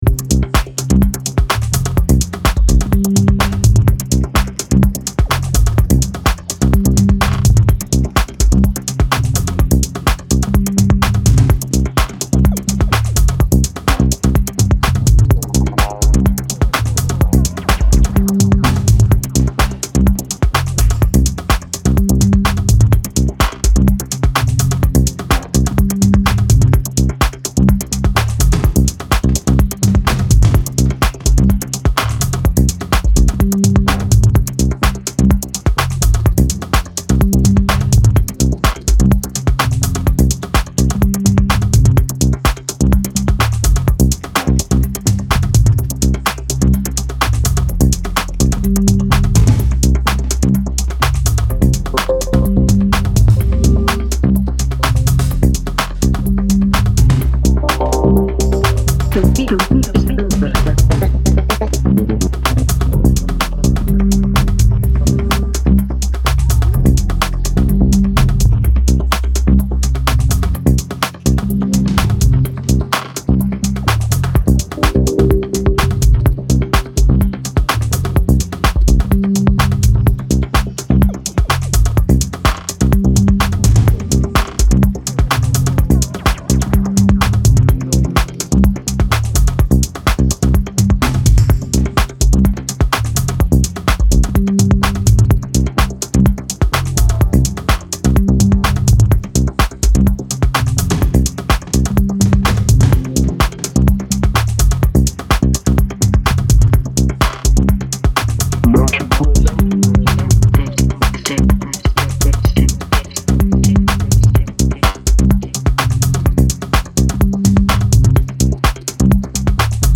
いずれもストイックに研ぎ澄まされたグルーヴ